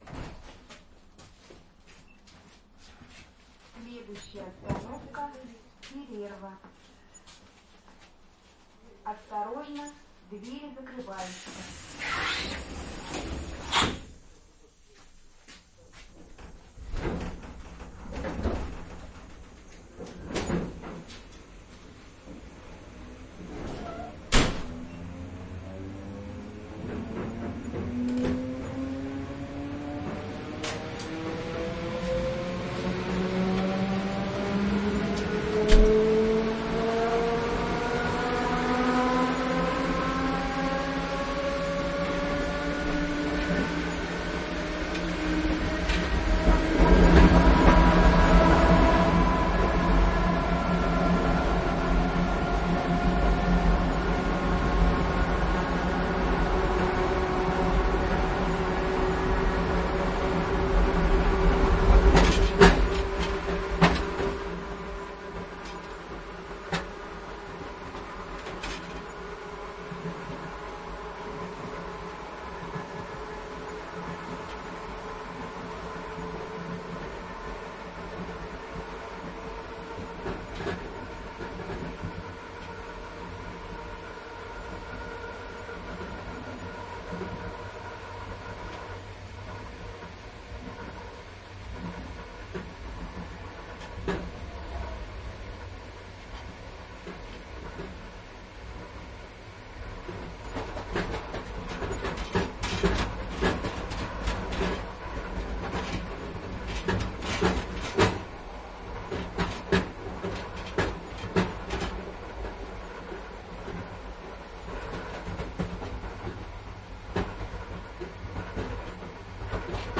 На этой странице собраны звуки электричек – от ритмичного стука колёс по рельсам до гула двигателя и сигналов перед отправлением.
Атмосферные звуки путешествия в российской электричке